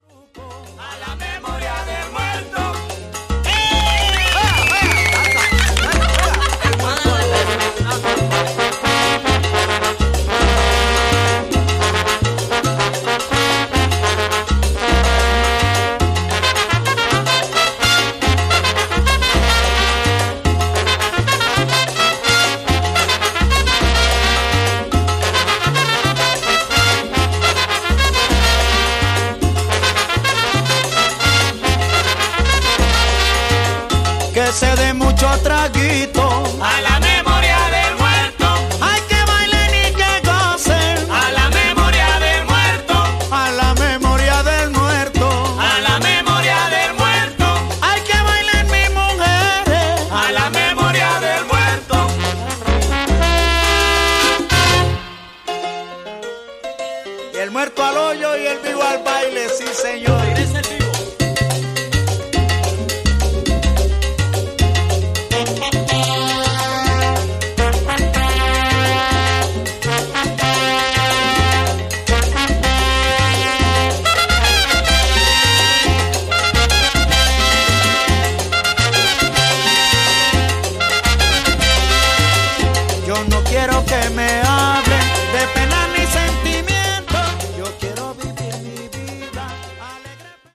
His voice is raw, expressive, and instantly recognisable.